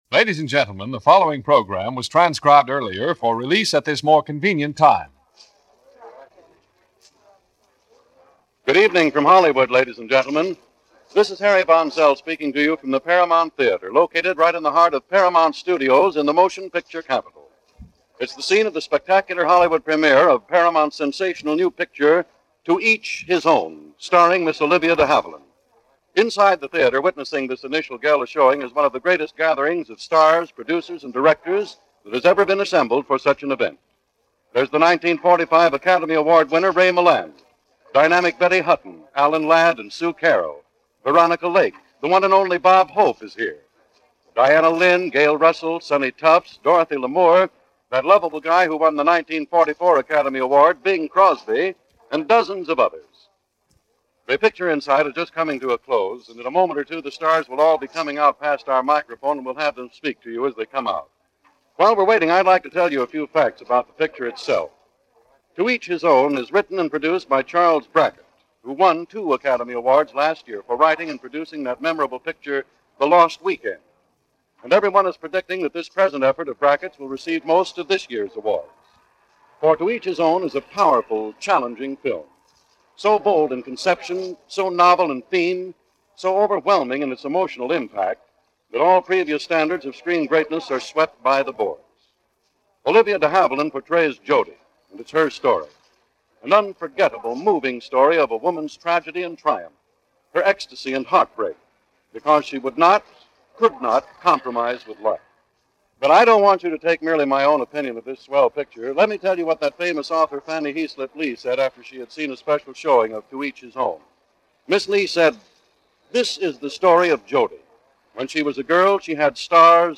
Hollywood Goes To A Premier - 1946 - Past Daily Weekend Gallimaufry - Premier at Paramount Studios Theatre of To Each His Own.
Obviously a marketing ploy; the stars would amble by the reporter’s microphone and utter superlatives, just so long as they were able to promote their own films as trade-off.